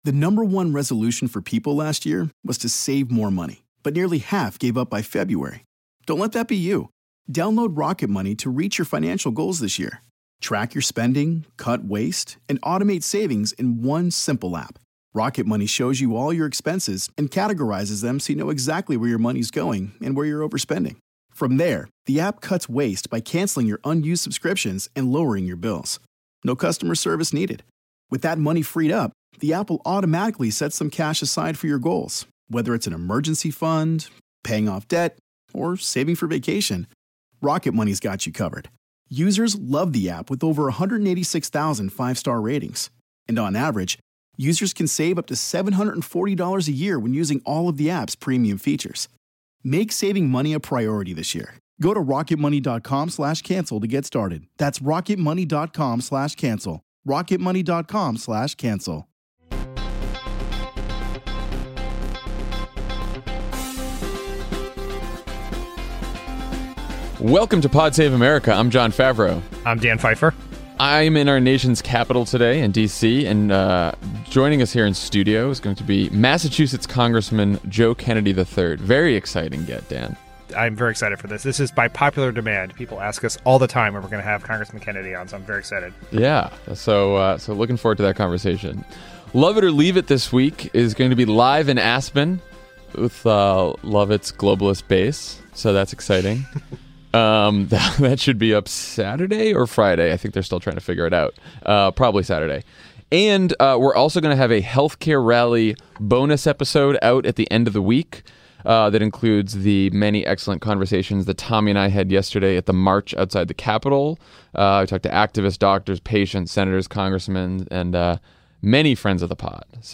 McConnell delays the wealthcare vote after a horrendous CBO score, and the resistance ramps up the pressure on wavering Republican Senators. Then, Jon and Dan talk to Massachusetts Congressman Joe Kennedy III about his views on health care, public service, and the future of the Democratic Party.